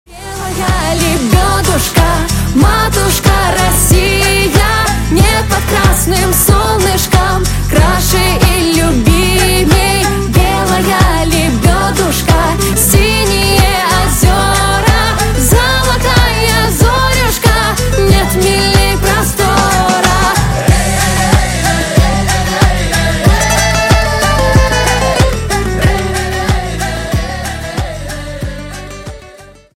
# Поп Рингтоны